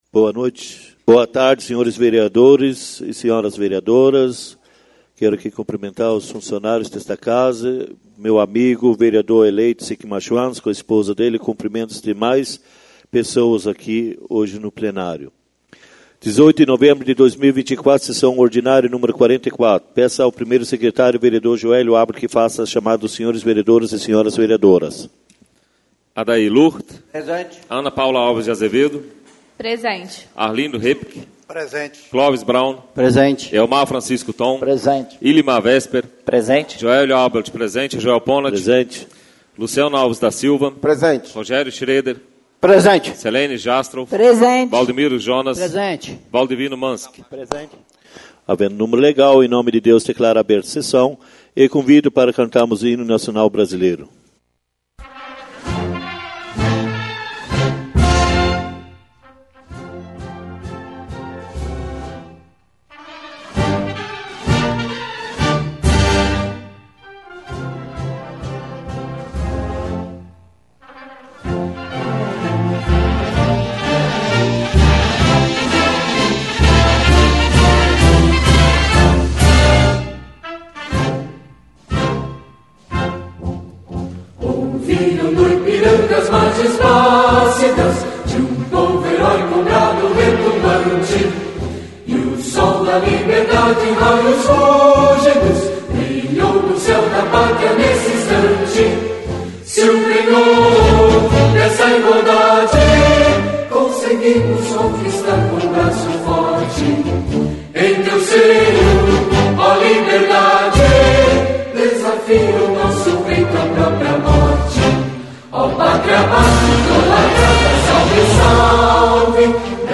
SESSÃO ORDINÁRIA Nº 44/2024